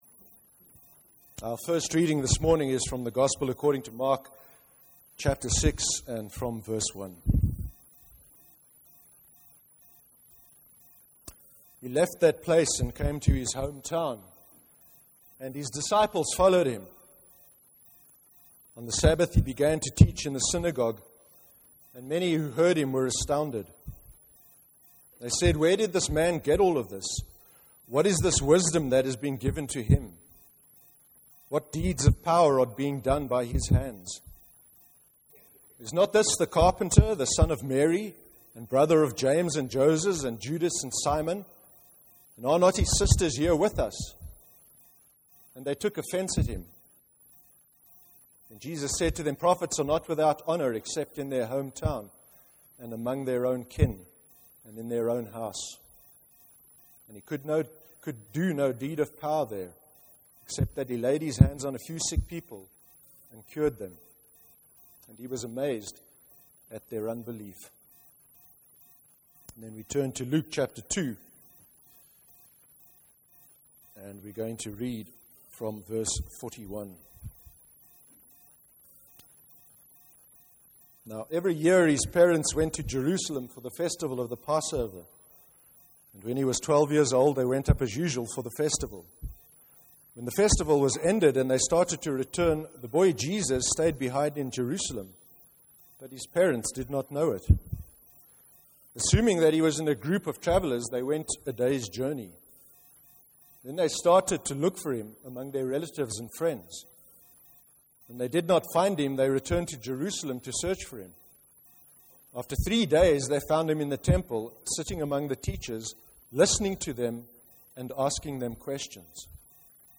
A recording of the service’s sermon is available to play below, or by right clicking on this link to download the sermon to your computer.